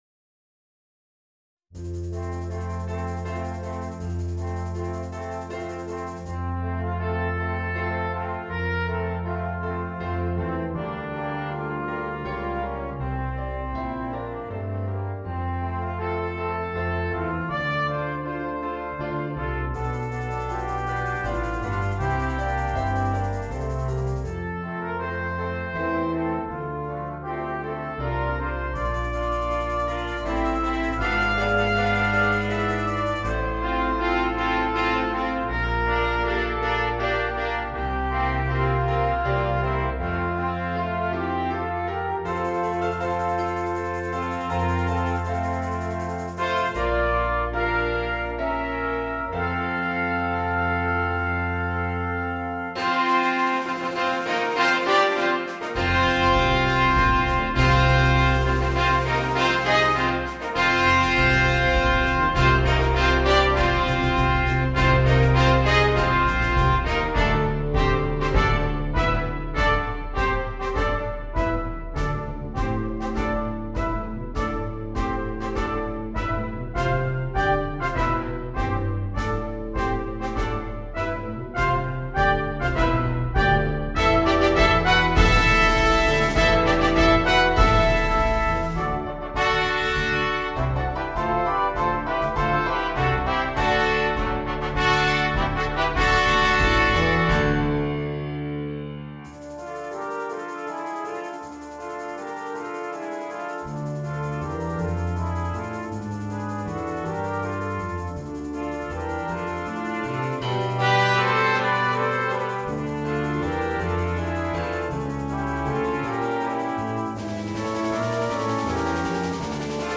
Gattung: Brass Quartet
3'32 Minuten Besetzung: Ensemblemusik für 4 Blechbläser PDF